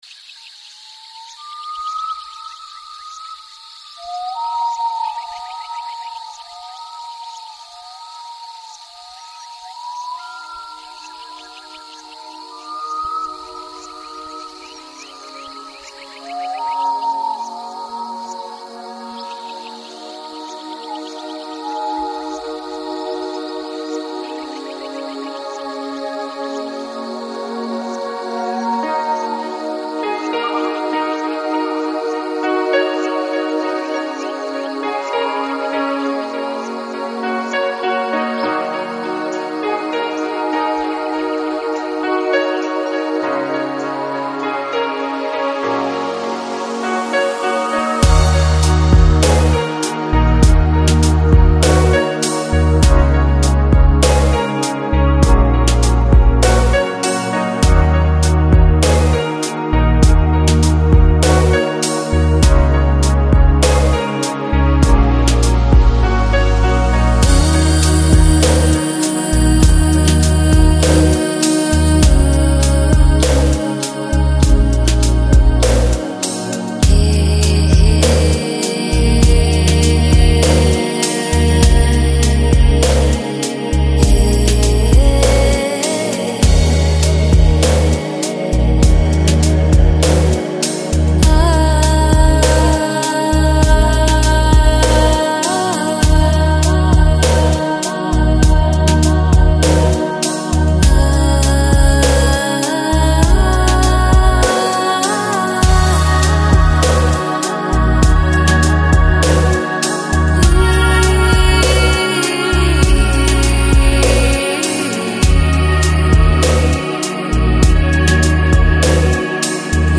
Electronic, Lounge, Chill Out, Enigmatic